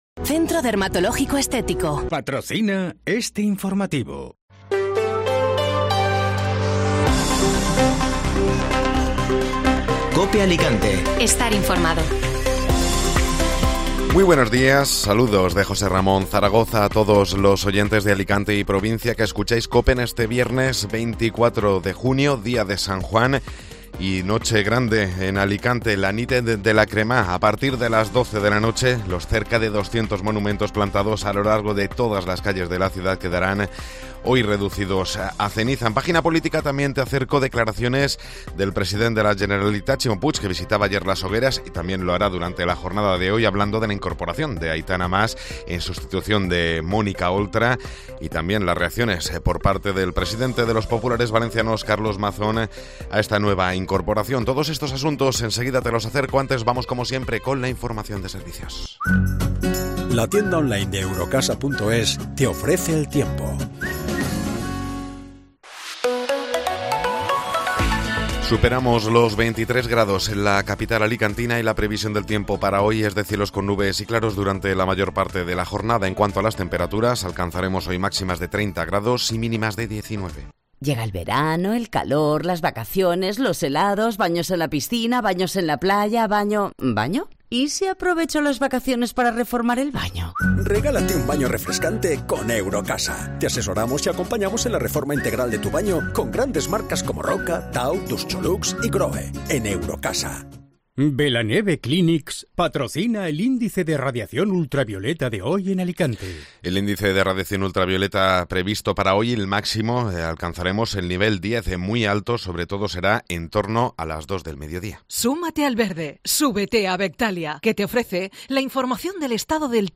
Informativo Matinal (Viernes 24 de Junio)